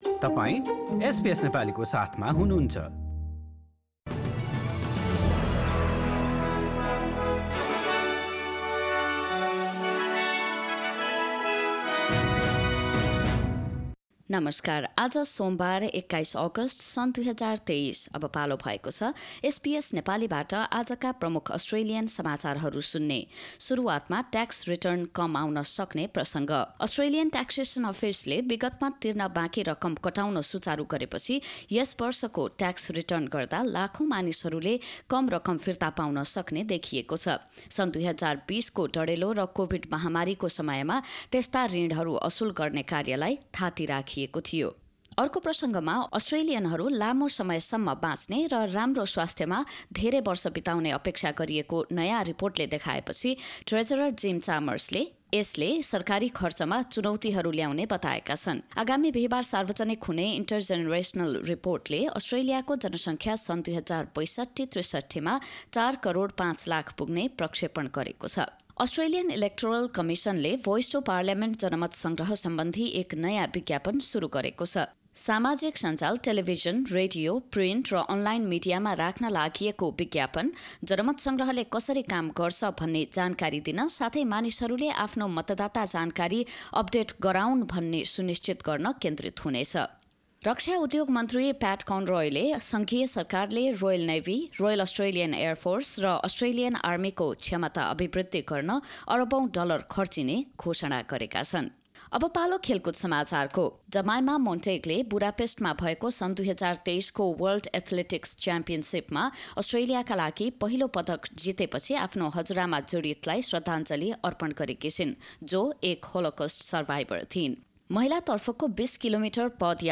SBS Nepali Australian Headlines: Monday, 21 August 2023